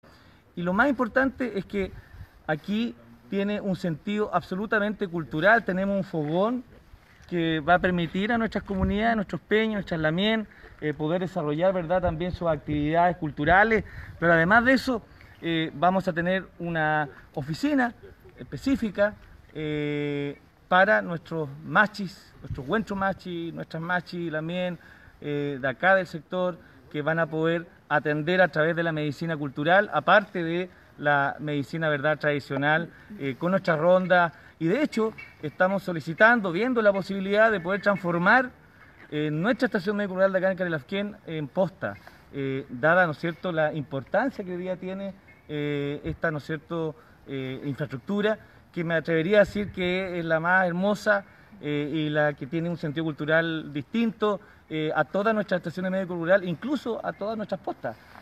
“Esta obra, que se realiza con pertinencia cultural, cuenta con un fogón, para que puedan realizar actividades culturales y además también se construyó una oficina para la atención de la Machi, Wentru Machi, a sus pacientes” indicó el alcalde Jorge Jaramillo Hott.
cuña-alcalde-carilafquen.mp3